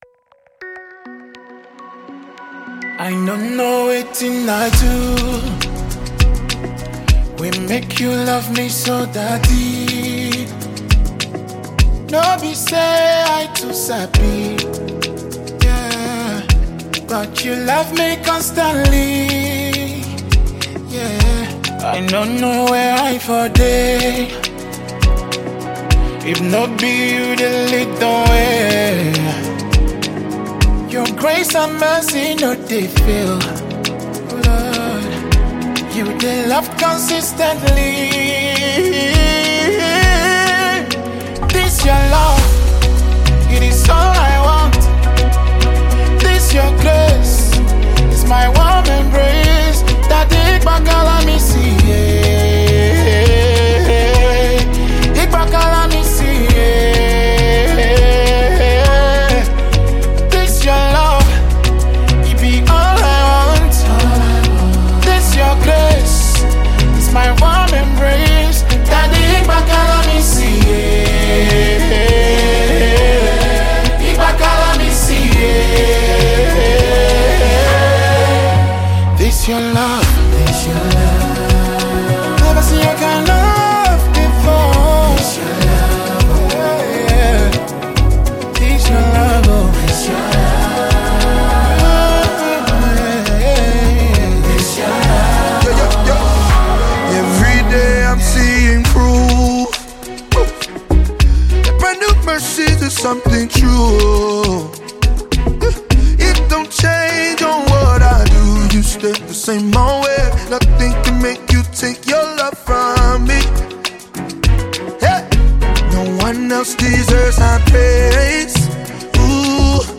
Renowned Nigerian gospel artist